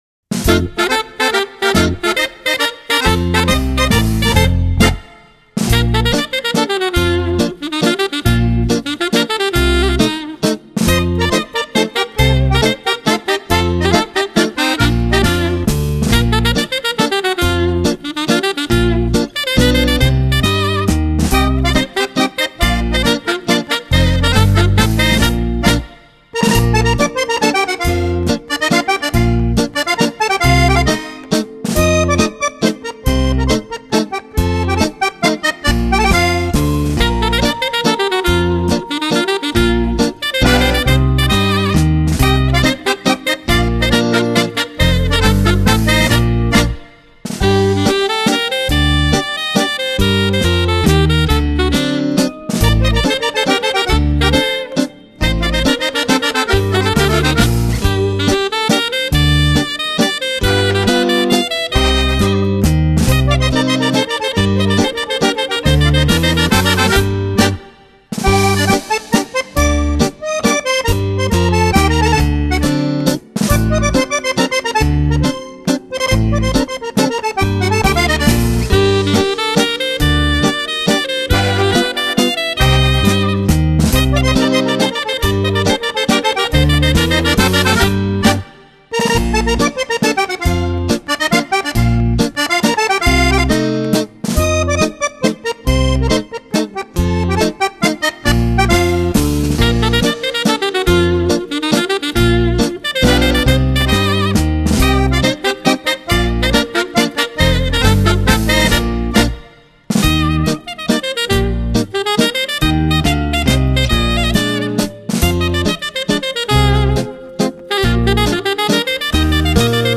Genere: Mazurka sax